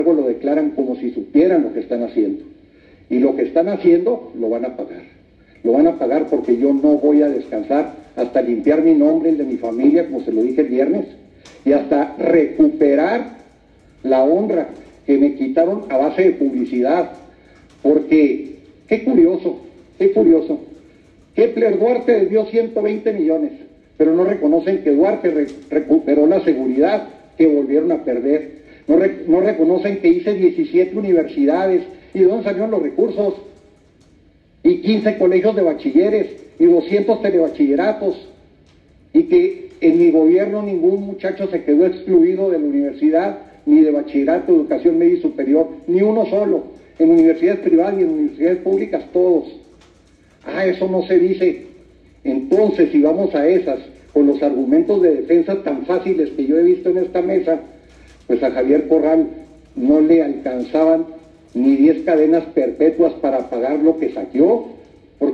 El exgobernador César D. J., vinculado a proceso por peculado y desvío de recursos públicos, aseguró que no descansará hasta limpiar su nombre y el de su familia, durante la audiencia intermedia que se celebró ayer, ahora por el caso de la empresa Kepler.